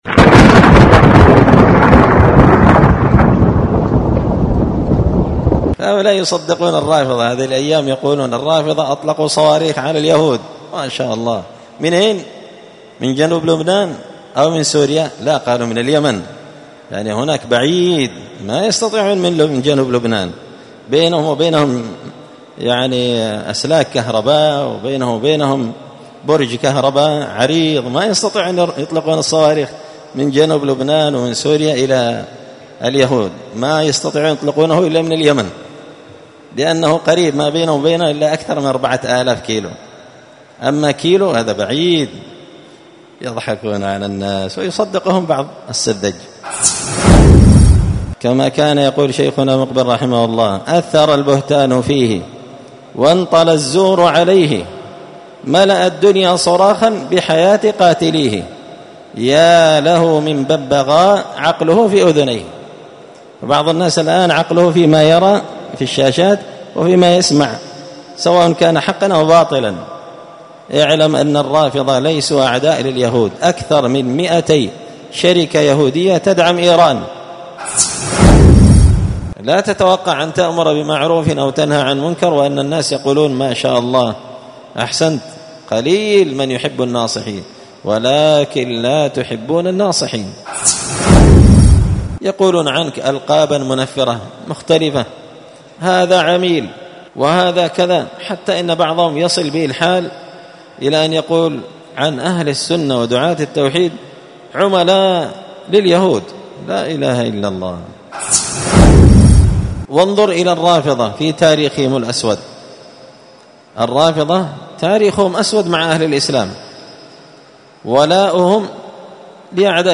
*كلمة قيمة وفريدة من نوعها حول حديث ثوبان (ولكنكم غثاء كغثاء السيل)*